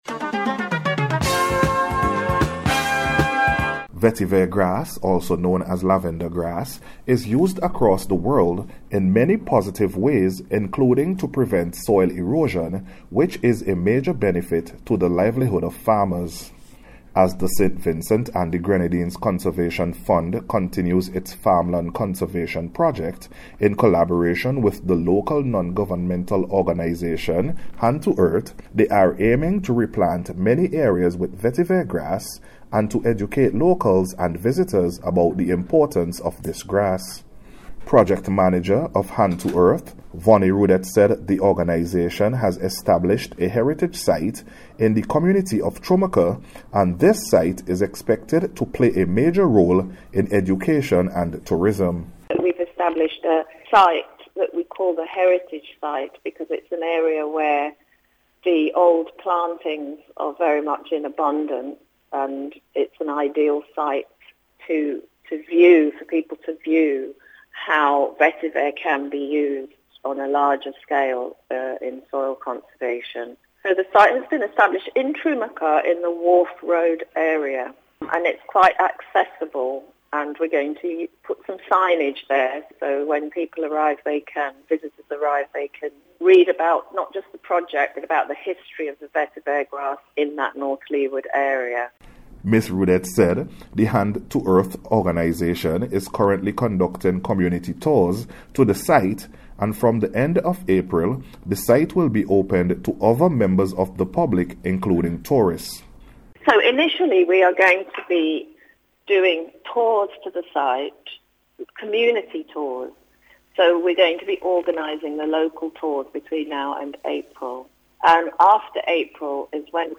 VETIVER-GRASS-TOURISM-PROJECT-REPORT.mp3